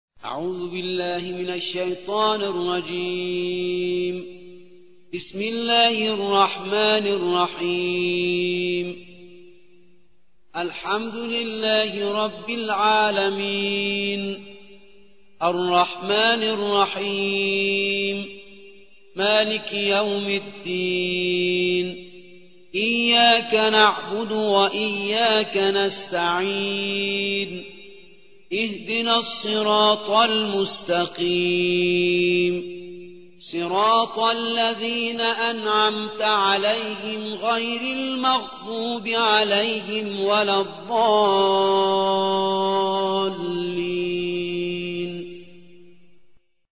1. سورة الفاتحة / القارئ